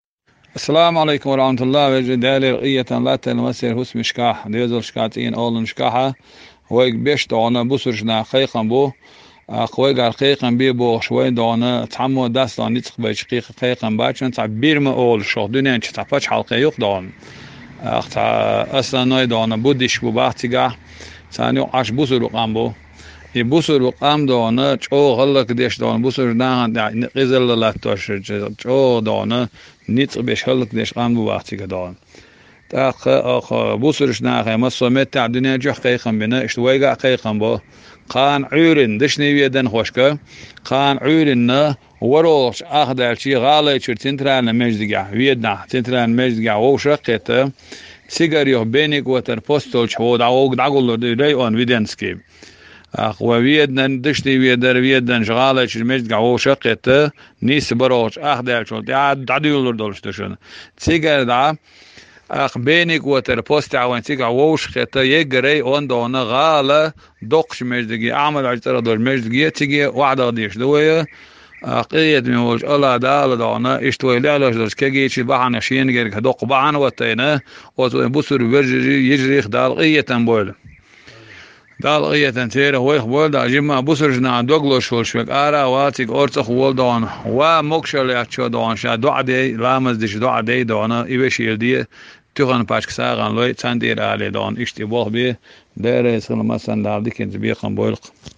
Ведана кIоштарчу цхьана туркхо дIасабаржийна хIара хаам.